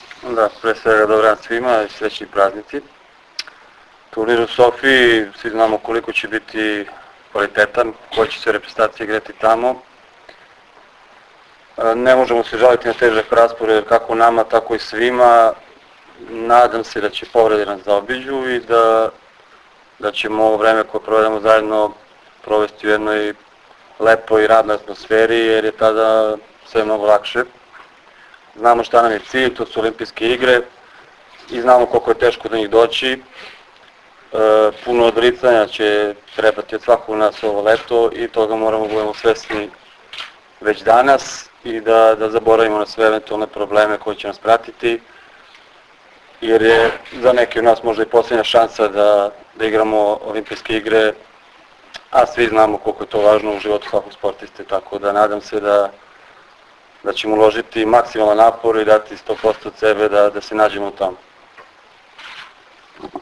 IZJAVA BOJANA JANIĆA